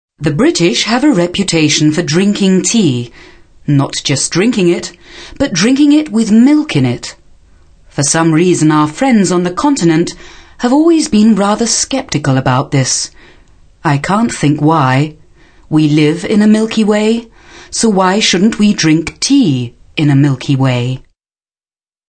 mid-atlantic
Sprechprobe: Werbung (Muttersprache):
female voice over artist german